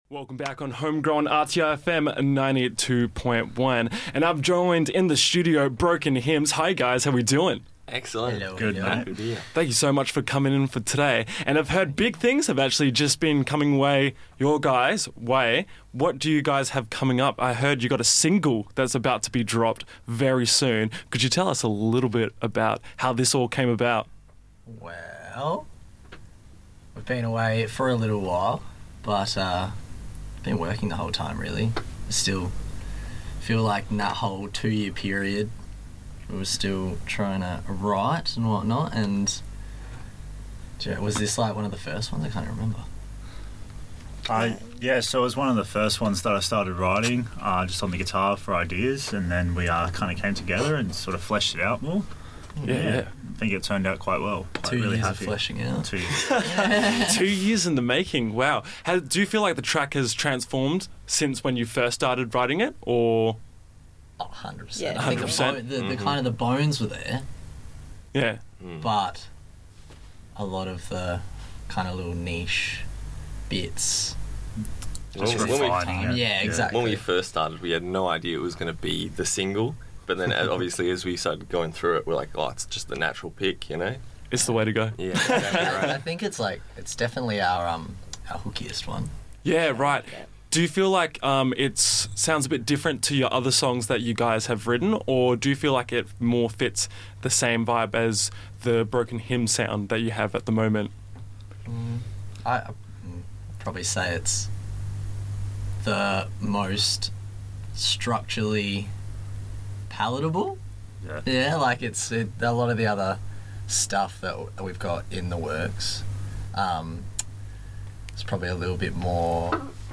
Broken-Hymns-interview.mp3